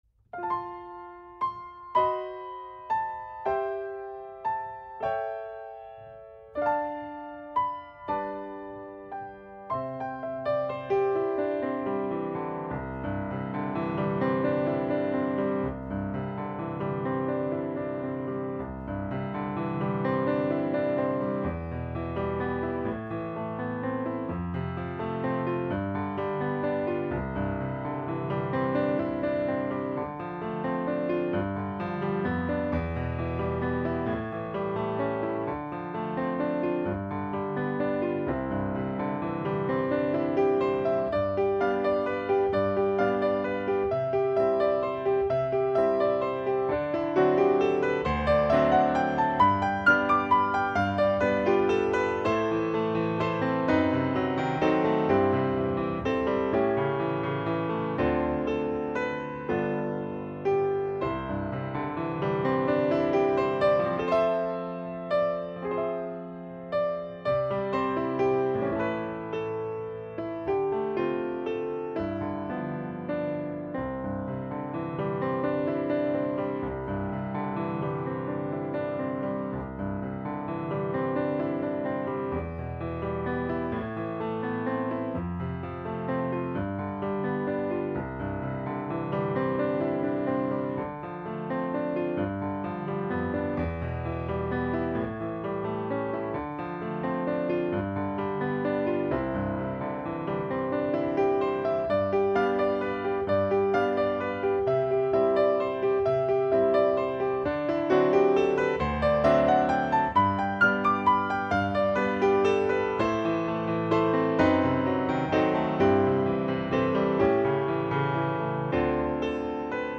ピアノ伴奏のみ
yumekonodaichipiano.mp3